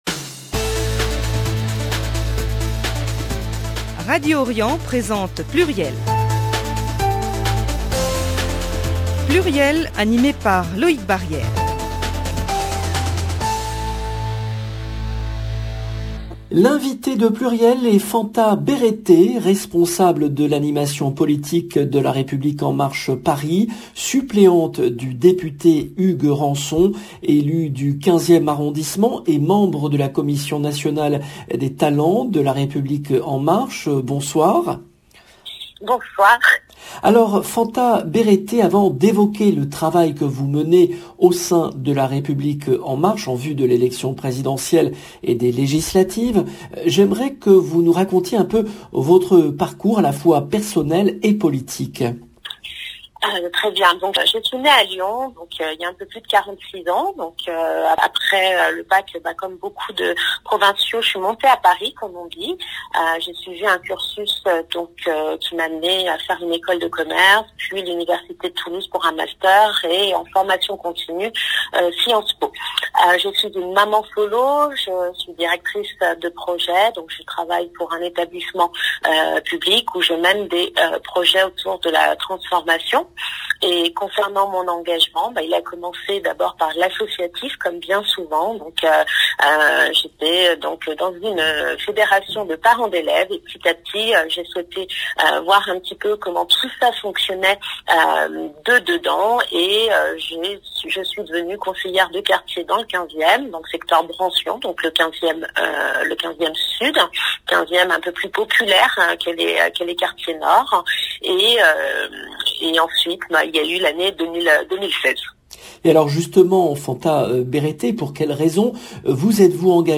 L’invitée de PLURIEL est Fanta Berete , responsable de l’animation politique de LREM-Paris, suppléante du député Hugues Renson, élue du 15e arrondissement et membre de la commission nationale des talents de LREM